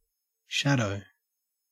shadow-au.mp3